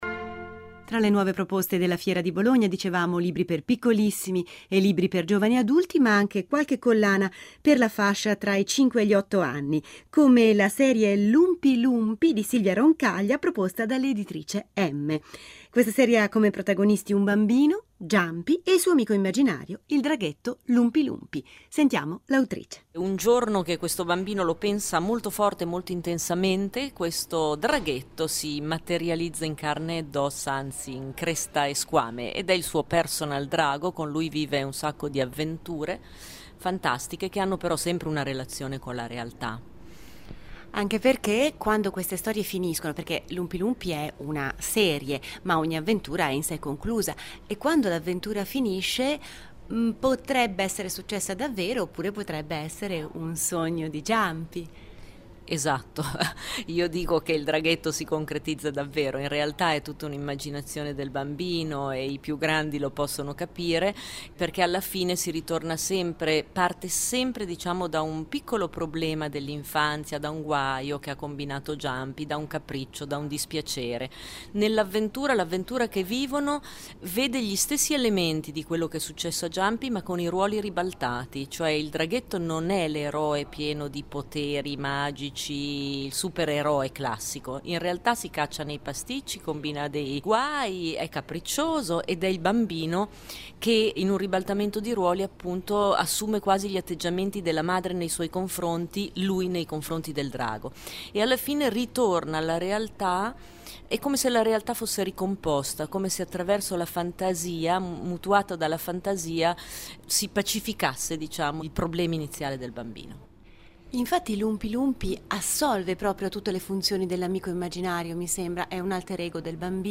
Intervista sulla serie “Lumpi Lumpi il mio amico drago” da Geronimo della RadioTelevisioneSvizzera